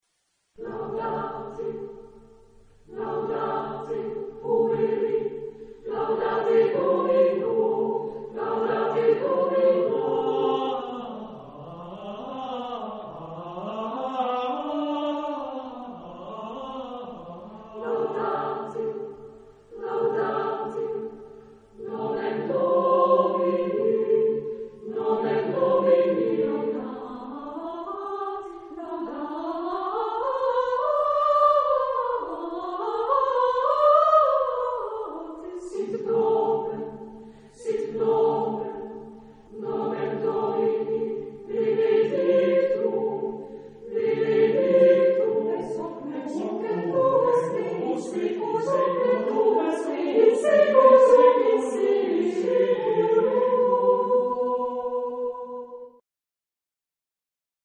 Genre-Style-Form: Psalm ; Contemporary ; Sacred
Type of Choir: SATB  (4 mixed voices )
Tonality: D minor ; A minor